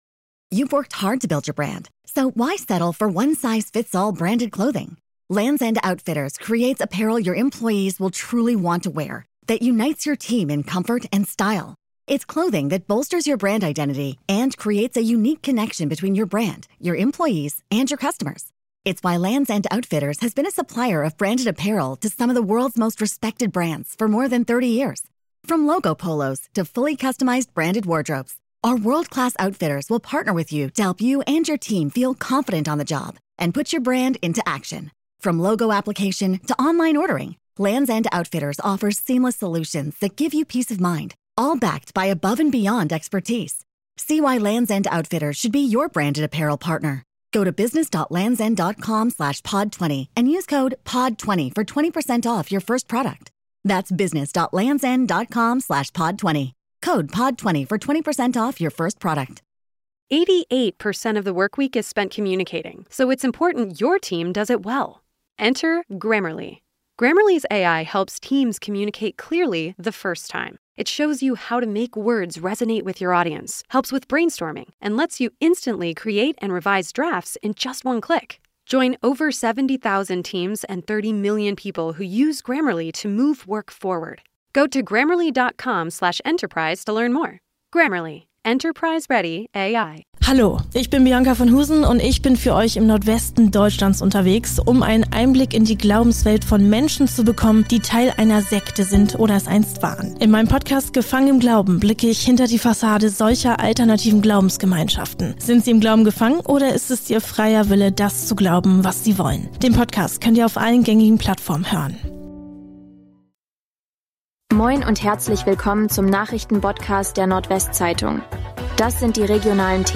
NWZ Nachrichten Botcast – der tägliche News-Podcast aus dem Norden